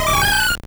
Cri de Feuforêve dans Pokémon Or et Argent.